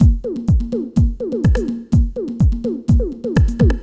Index of /musicradar/retro-house-samples/Drum Loops
Beat 18 Full (125BPM).wav